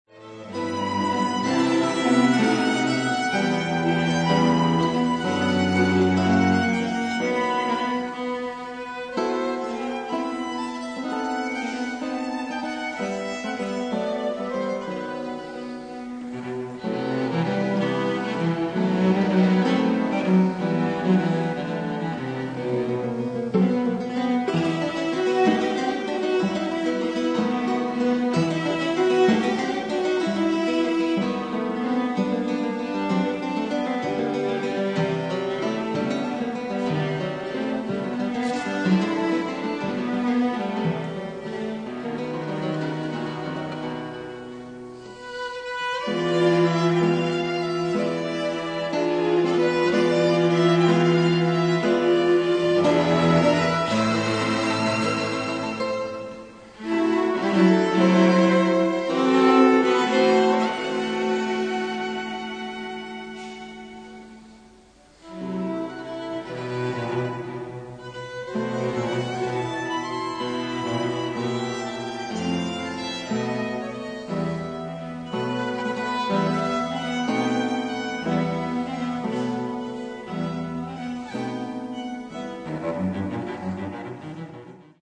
Cataluña from Suite española (live) sample 1'24'' (guitar and string trio)